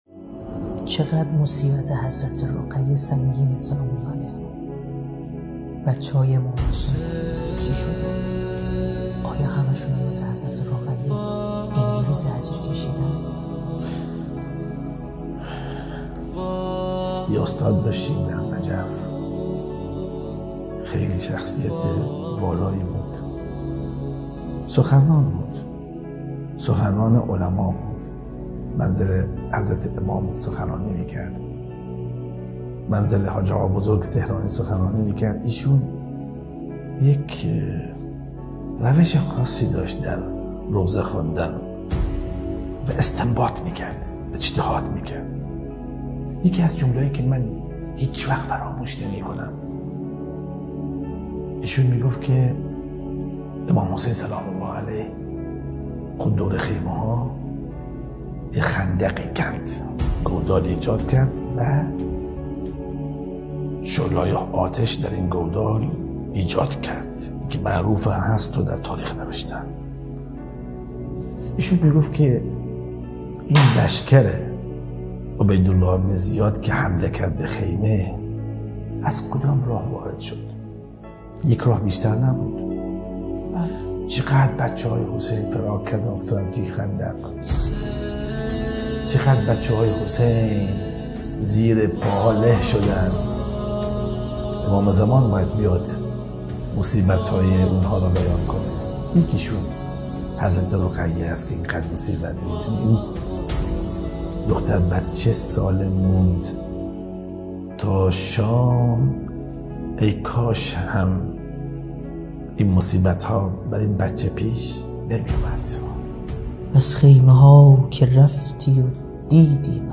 برنامه گفت و گویی حرف آخر